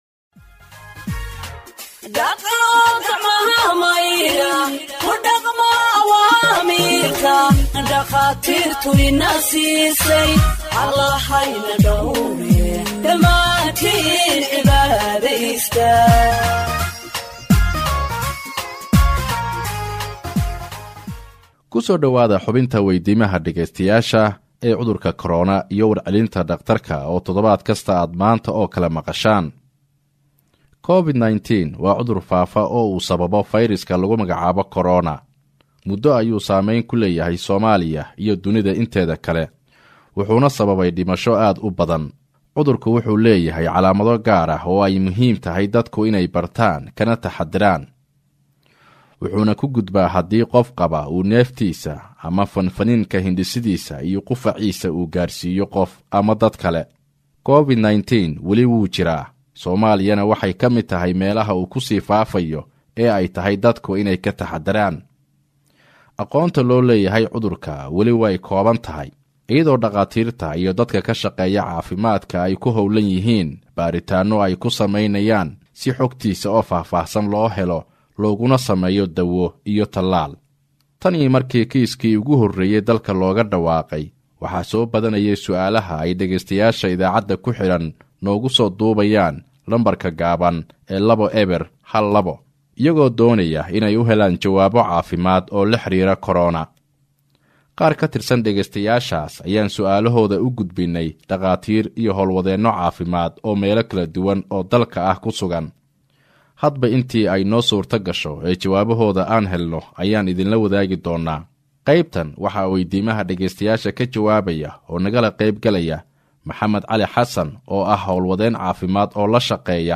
HEALTH EXPERT ANSWERS LISTENERS’ QUESTIONS ON COVID 19 (49)
Radio Ergo provides Somali humanitarian news gathered from its correspondents across the country for radio broadcast and website publication.